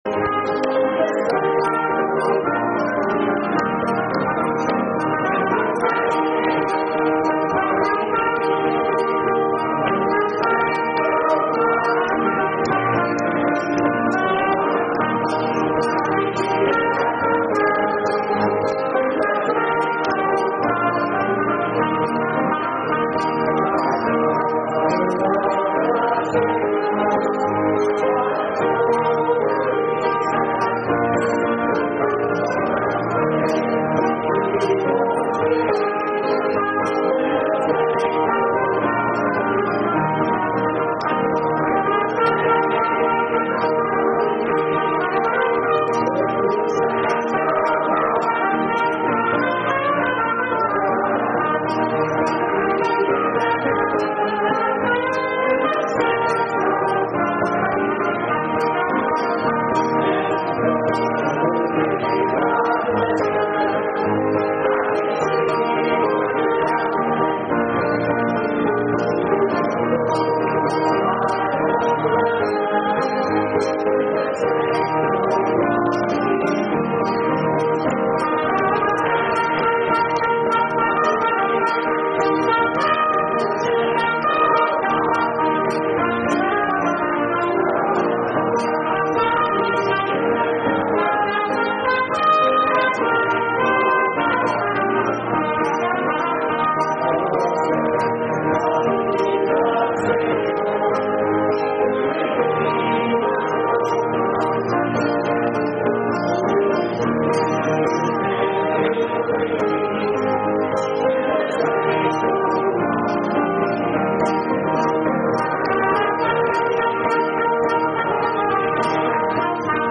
trumpet
with the Church singing